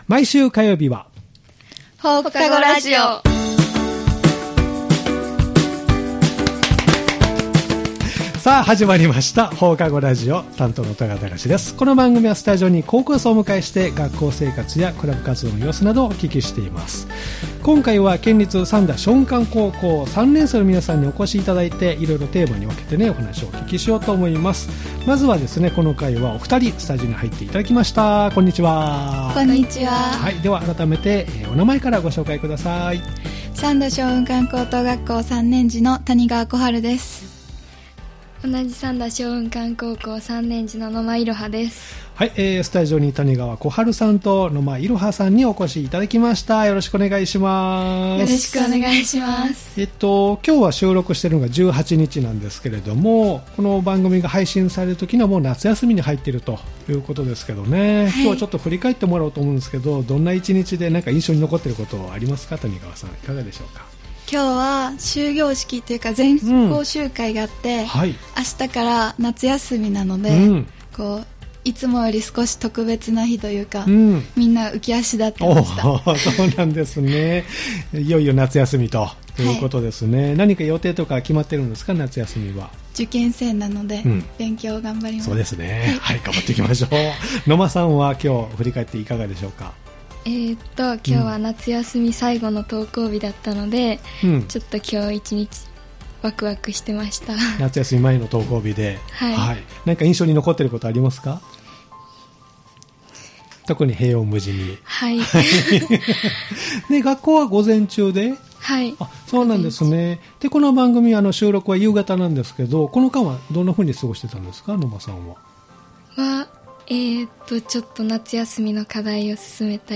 毎回高校生の皆さんをスタジオにお迎えして、学校生活、部活、学校行事などインタビューしています（再生ボタン▶を押すと放送が始まります）